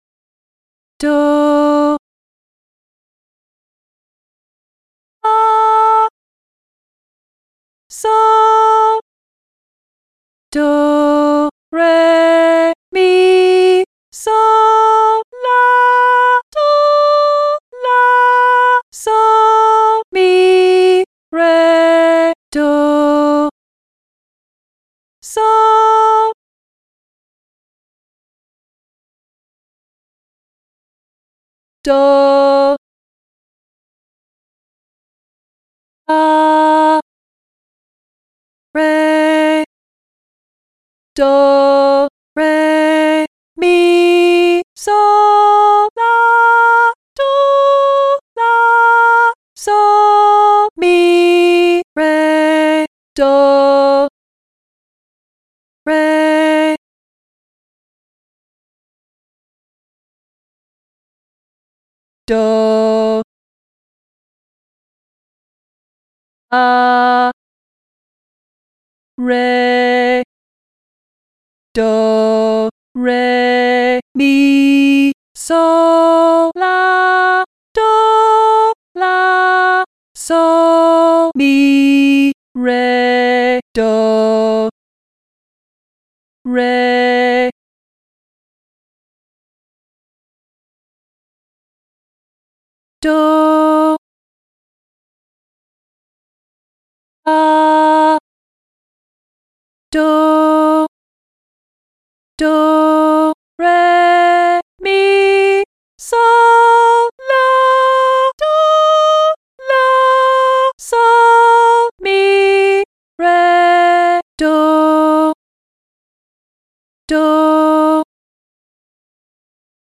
Female Voice
Hard Training Exercise: Major Pentatonic